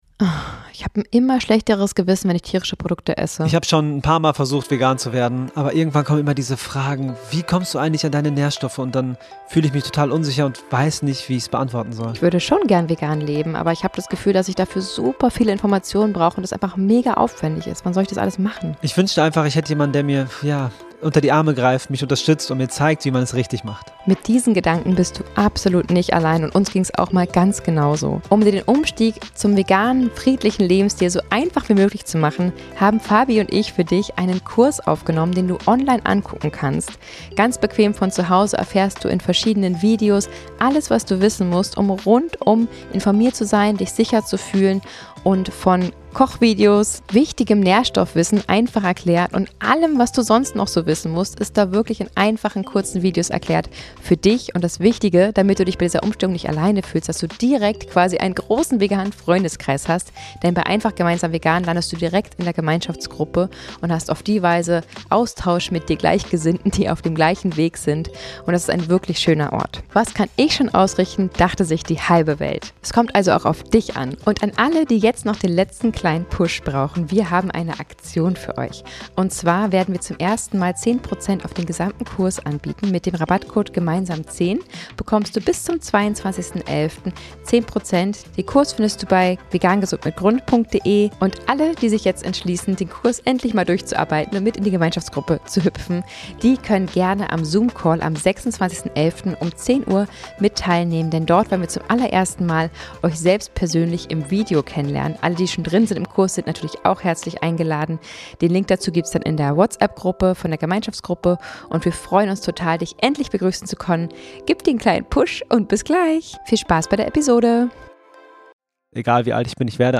In diesem Live Poddi bei der Veggieworld in Düsseldorf gibt es einige Themen, die unter die Haut gehen. Wir haben das erste mal im vollen Freestyle auf der Bühne gestanden und das Leben schreiben lassen. Das Ergebnis - viele aufrichtige Gefühle und sogar Tränen.